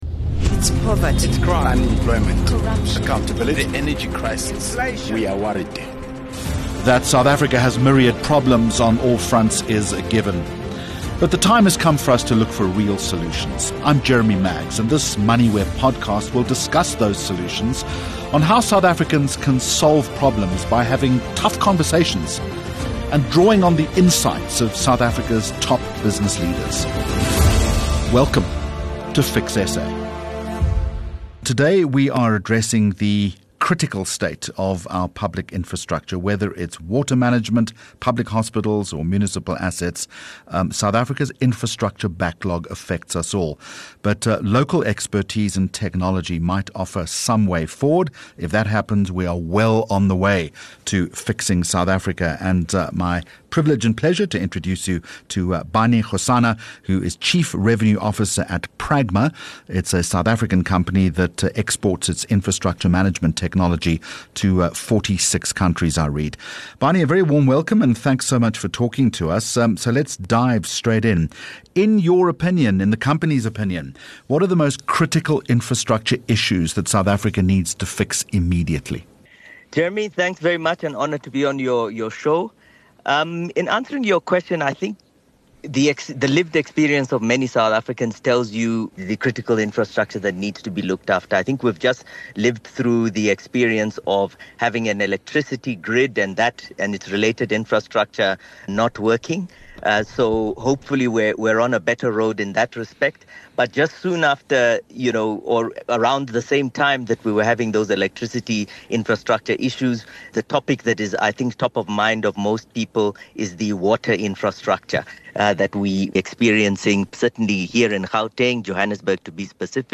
Moneyweb and Jeremy Maggs bring you robust conversations with South Africa’s top business leaders.